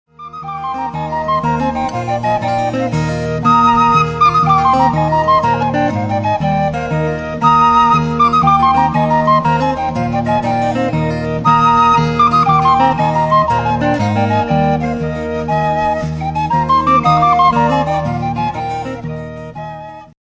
chitarra acustica